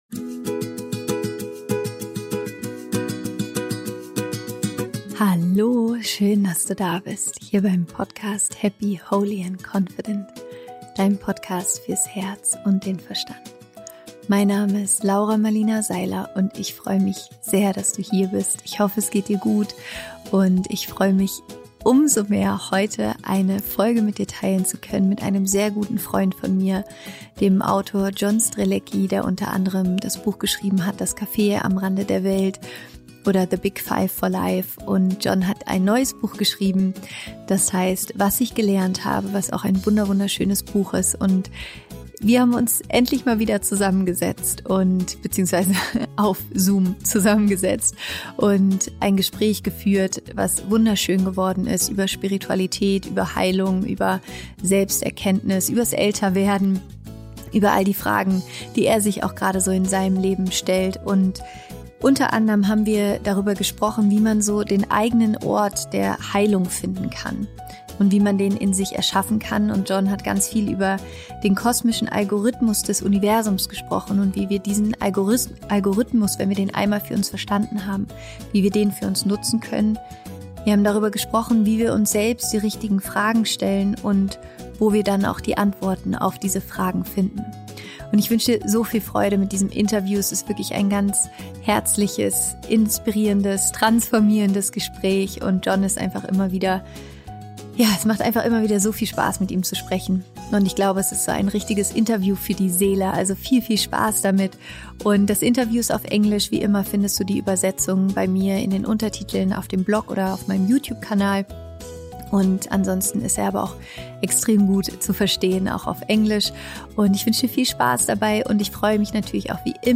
Wie du deinen Ort der Heilung findest – Interview Special mit John Strelecky